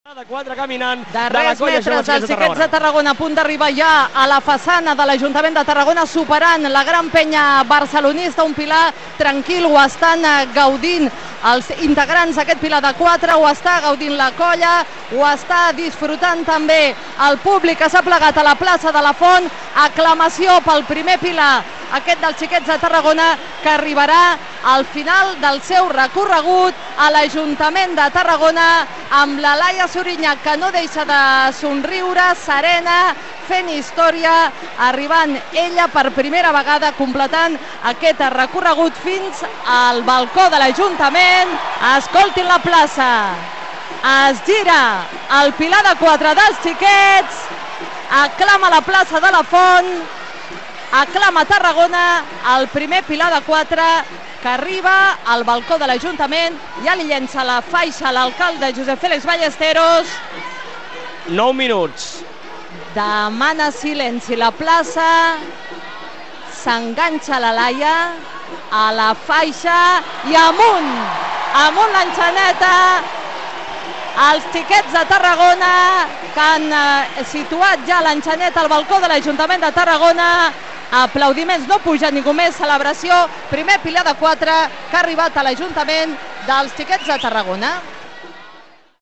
Transmissió castellera: pilar dels Xiquets de Tarragona - Tarragona Radio